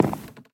wood4.ogg